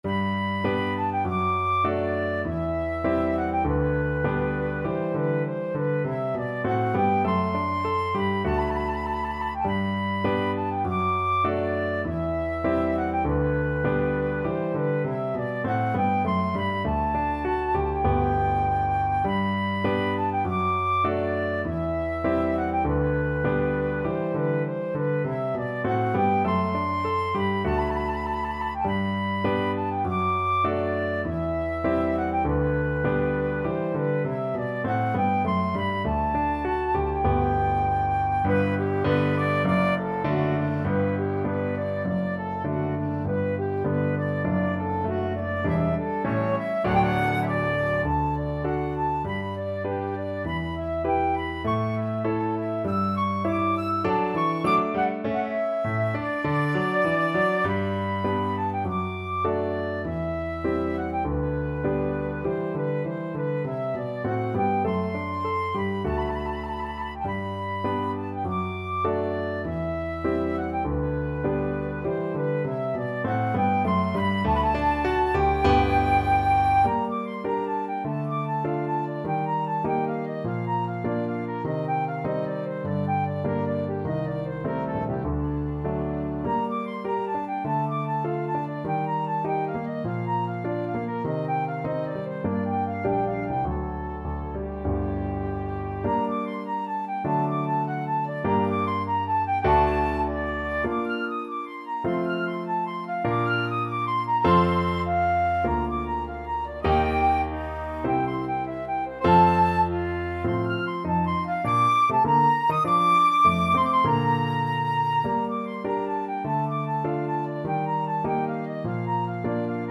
Flute
~ = 100 Allegretto
G major (Sounding Pitch) (View more G major Music for Flute )
2/2 (View more 2/2 Music)
Classical (View more Classical Flute Music)